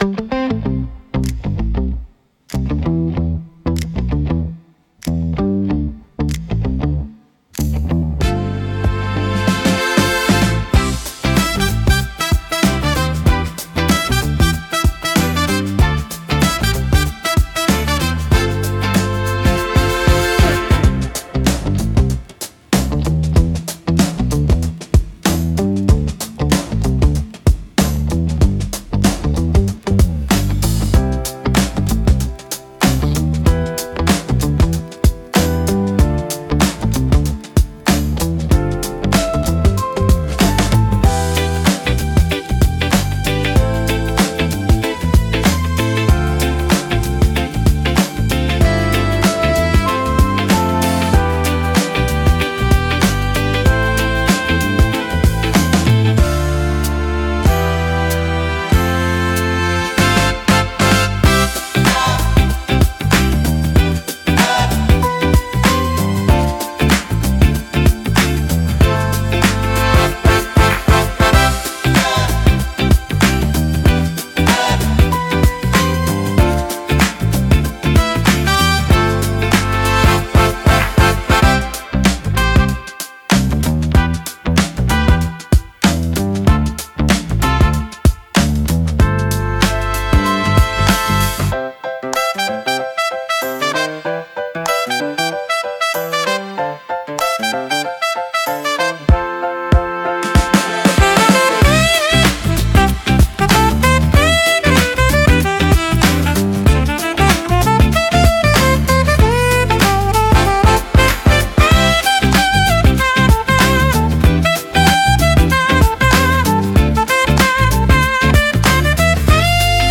明るい